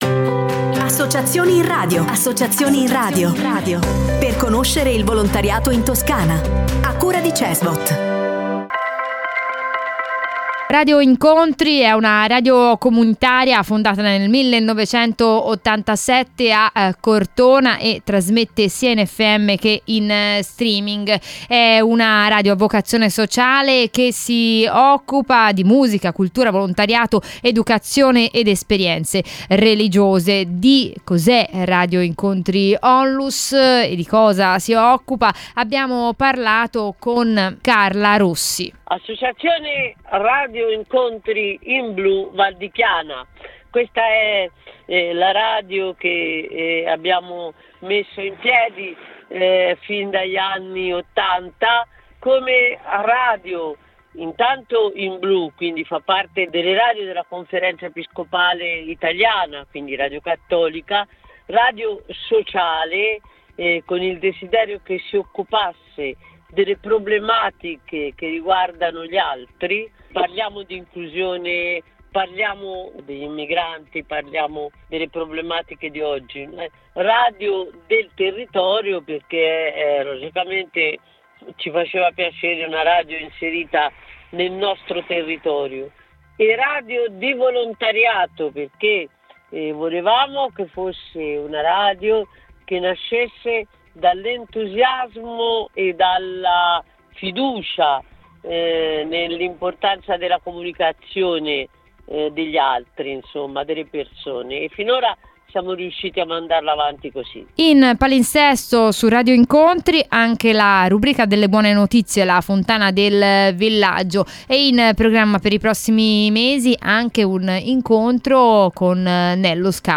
L’Associazione presenta “Voci Migranti”, un progetto radiofonico dedicato all’inclusione e alla sensibilizzazione sui temi della migrazione, attraverso testimonianze, storie e dialogo tra cittadini e migranti.